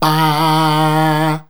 BAAAAAH A#.wav